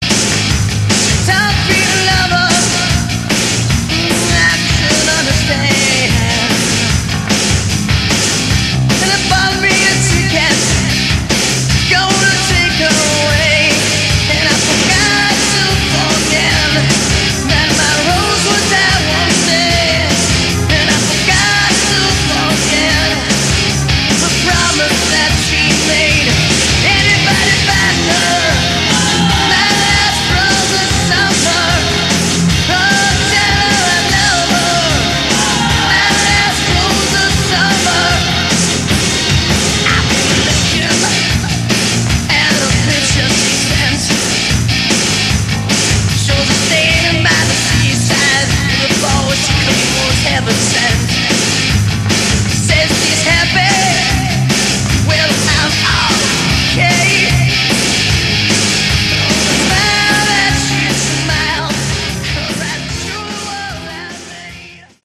Category: Hard Rock
lead vocals